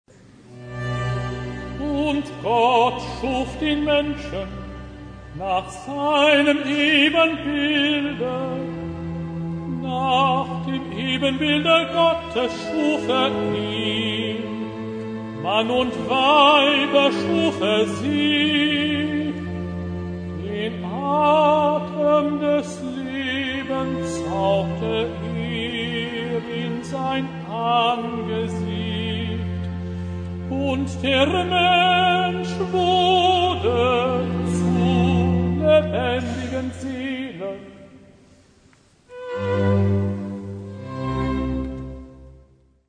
Die Schöpfung: Rezitativ Und Gott schuf den Menschen